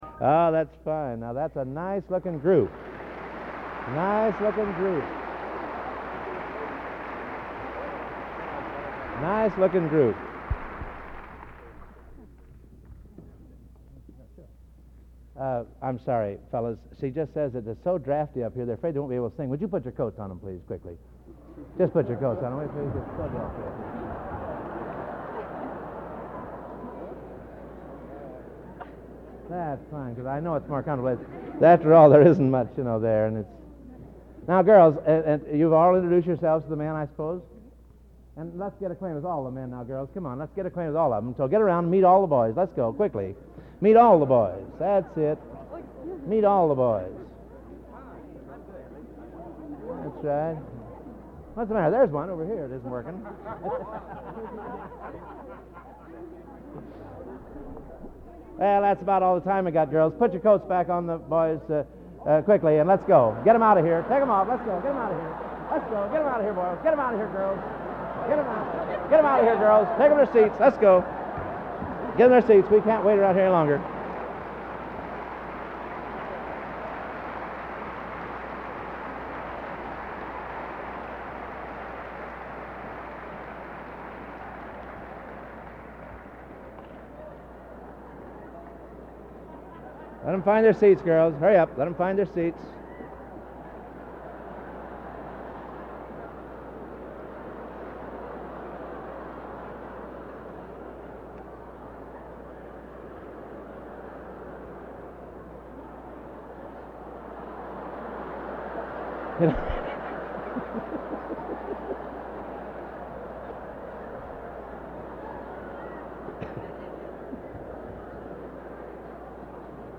Collection: Gala Concert, 1961
Genre: | Type: Director intros, emceeing